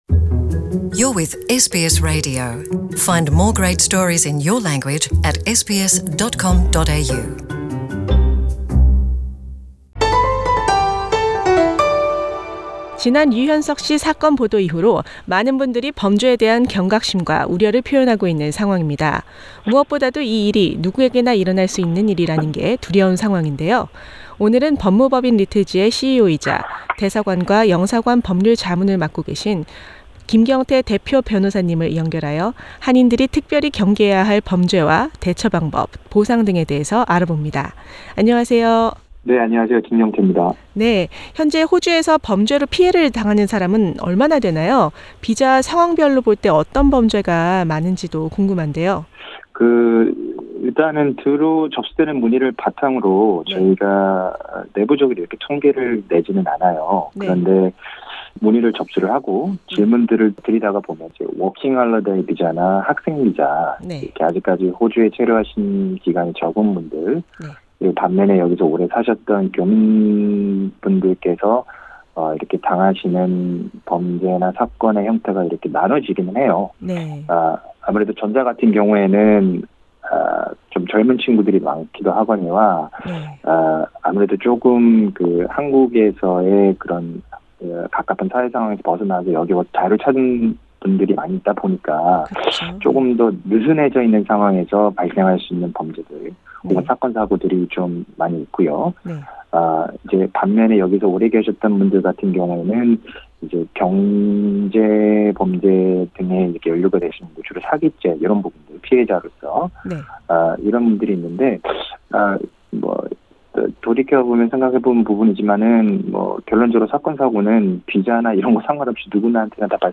[Legal advice] Korean victim